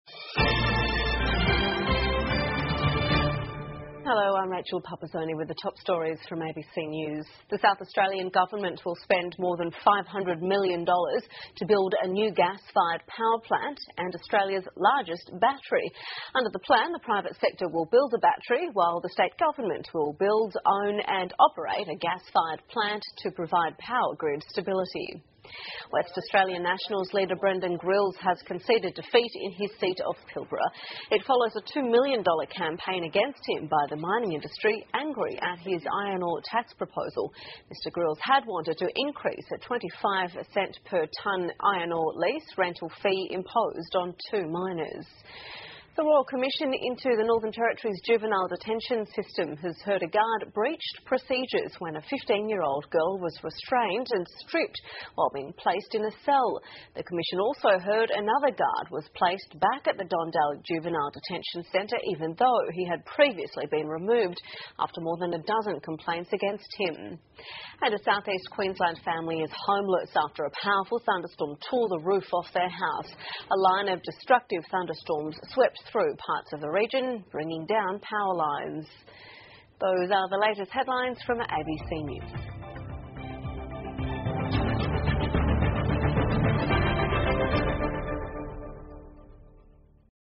澳洲新闻 (ABC新闻快递) 南澳大利亚建造燃气发电厂 雷暴天气侵袭昆士兰州 听力文件下载—在线英语听力室